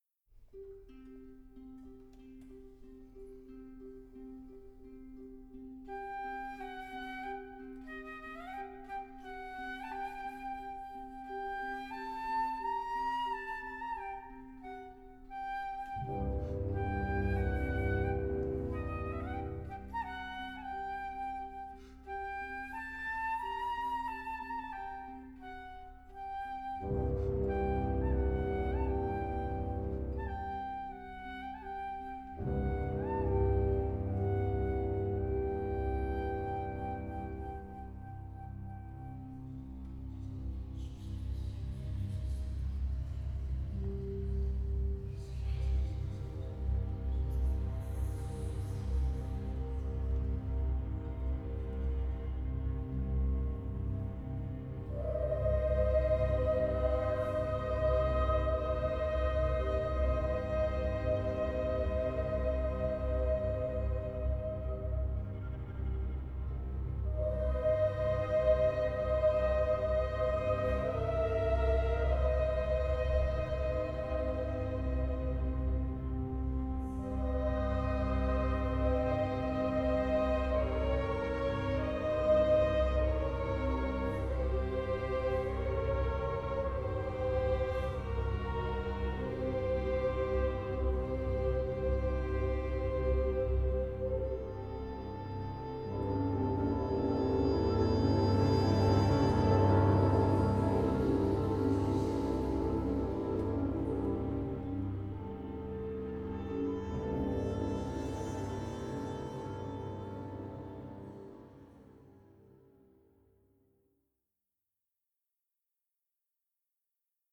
FLUTE CONCERTO